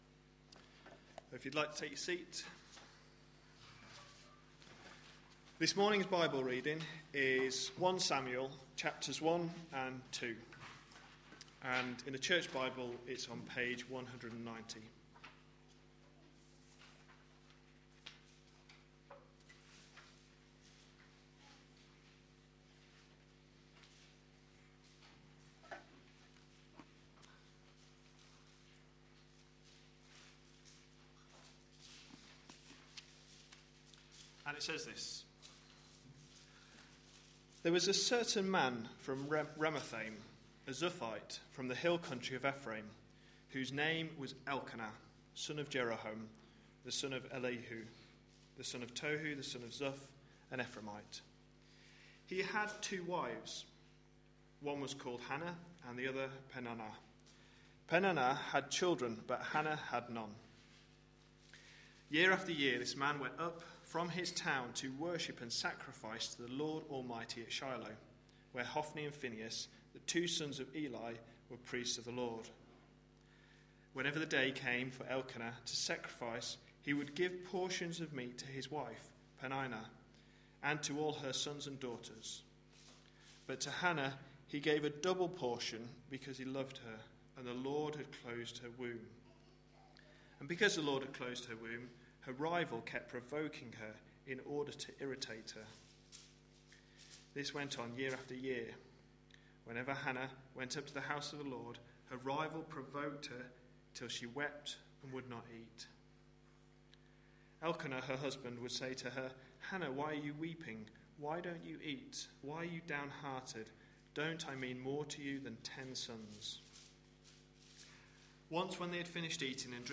A sermon preached on 7th April, 2013, as part of our God's King? series.